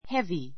heavy 中 A1 hévi ヘ ヴィ 形容詞 比較級 heavier héviə r ヘ ヴィア 最上級 heaviest héviist ヘ ヴィエ スト ❶ 重い ; 重苦しい ⦣ 「上からのしかかる重さが大きい」こと. a heavy suitcase a heavy suitcase 重いスーツケース Your bag is heavy, but mine is light.